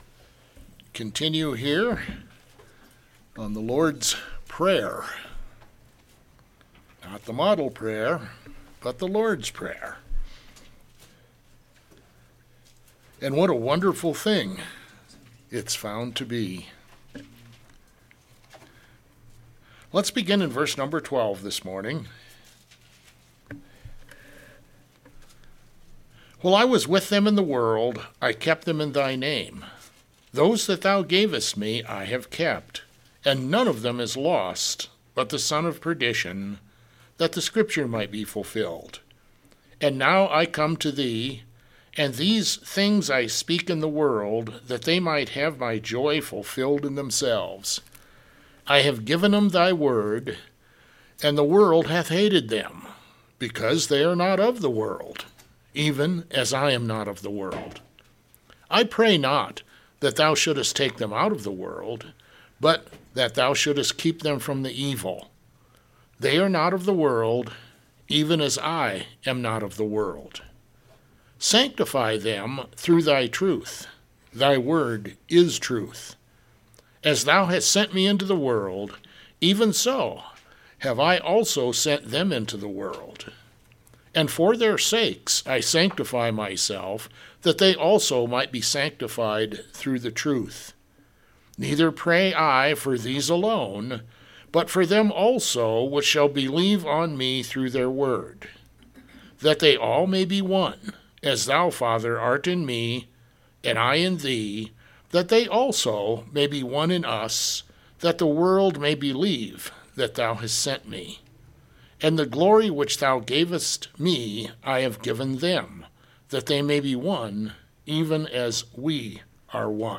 A Non-Denominational Bible Church in Black Forest, Colorado
Sermons